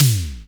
Index of /90_sSampleCDs/Roland L-CD701/TOM_E.Toms 1/TOM_Analog Toms1
TOM LONG T05.wav